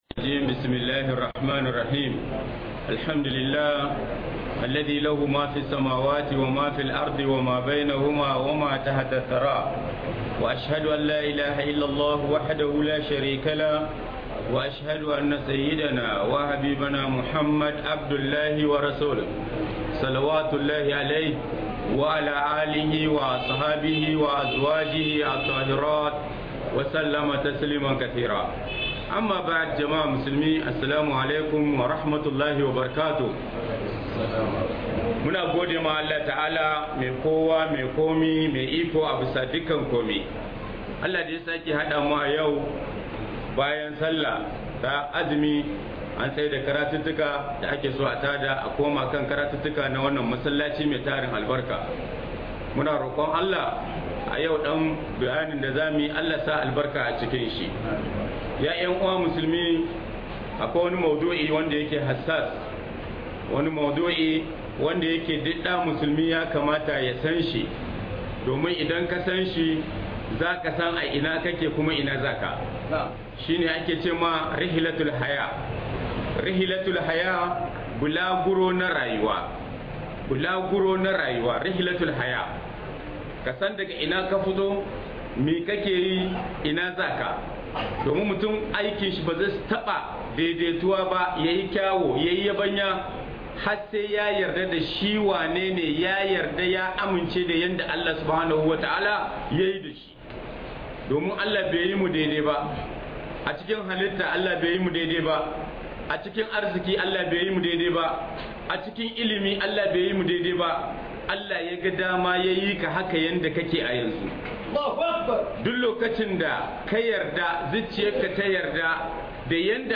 Bulaguron Rayuwa - Muhadara